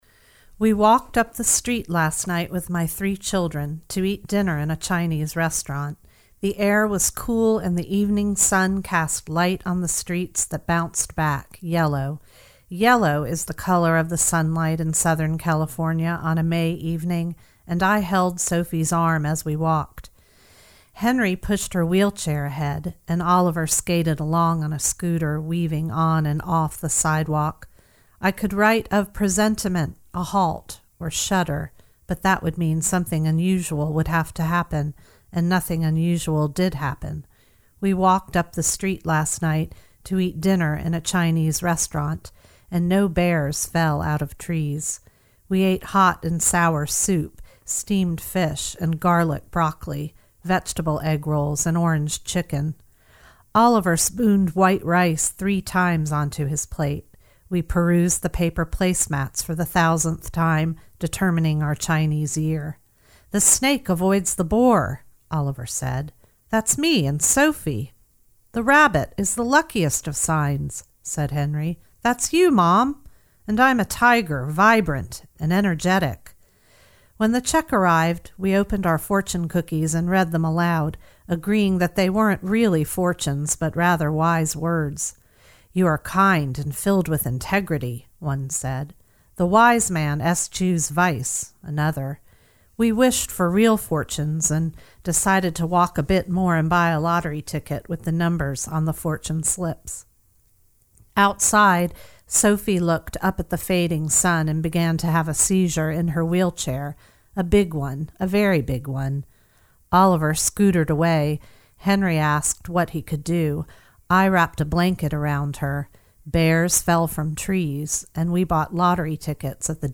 Your warm voice makes the words even richer.